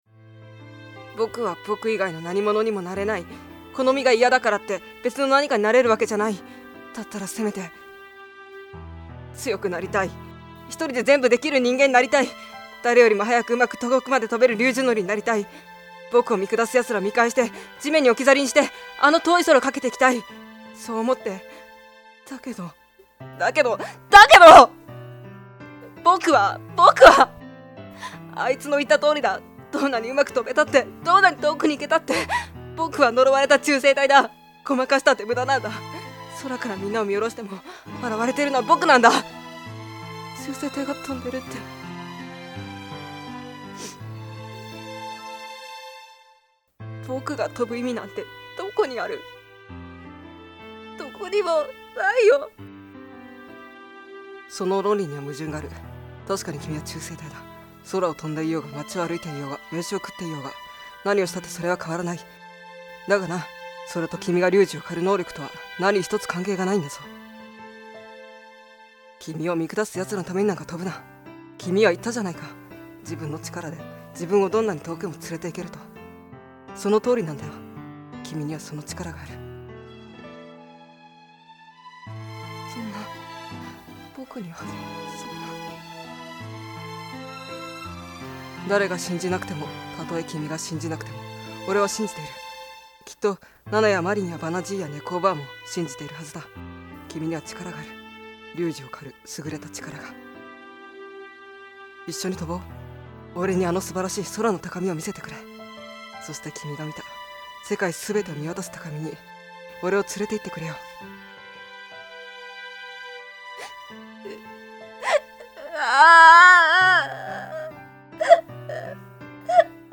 掛け合い
通しで録音したためあまり使い分けが出来ておらず、のちに消しに来るかもしれません
♪序盤（女の子が全体的にうるさい…ドタバタなシーン）